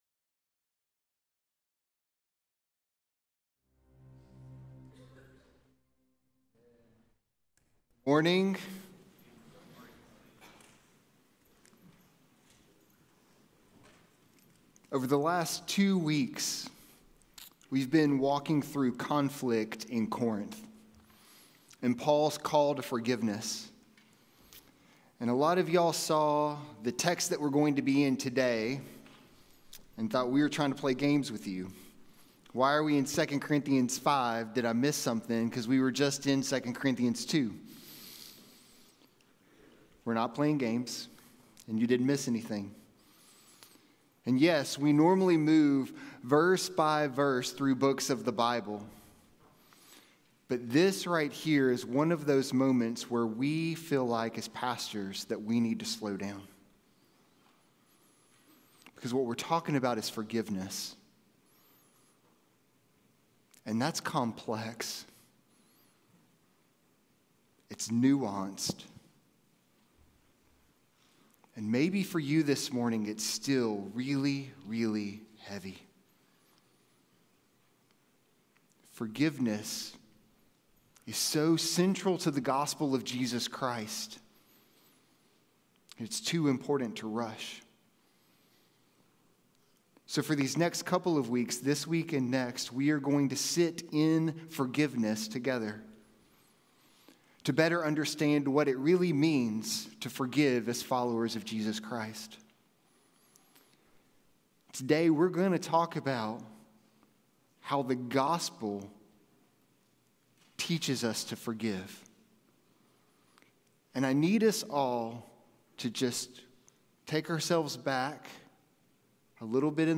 Sermons
Sermons from Treasuring Christ Church: Raleigh, NC